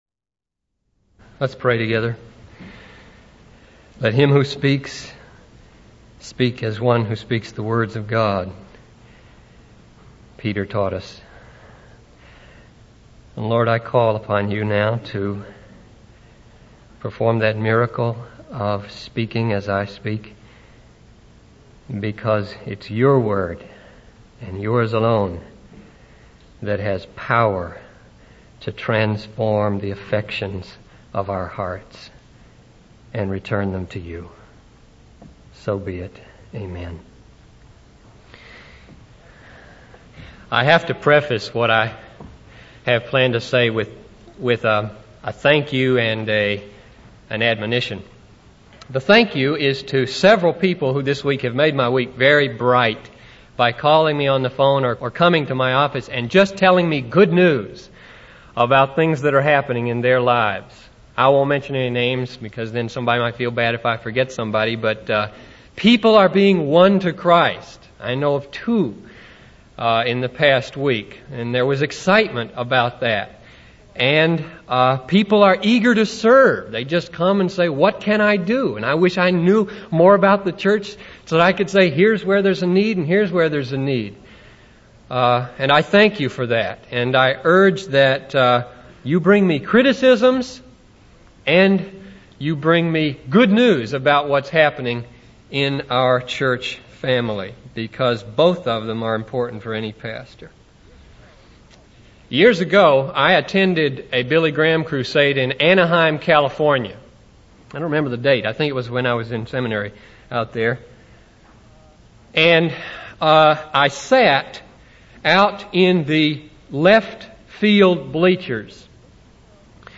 In this sermon, the speaker emphasizes the importance of praise and delight in our lives.